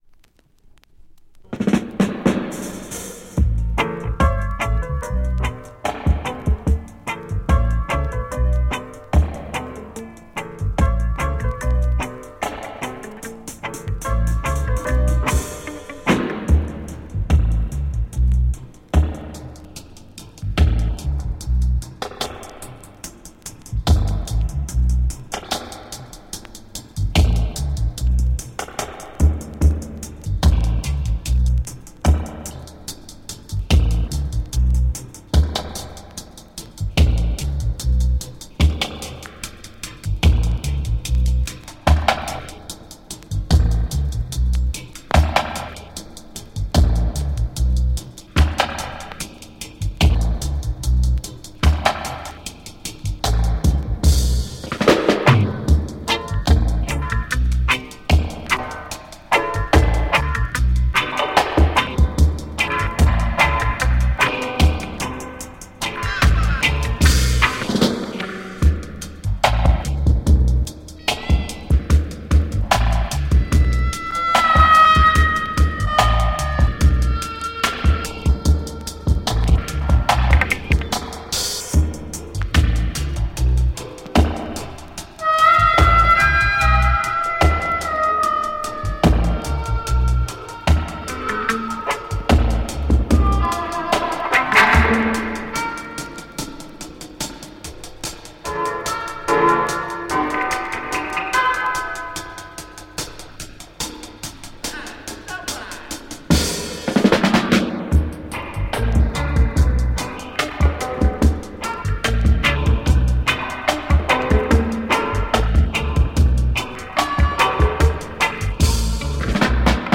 Reggae dub break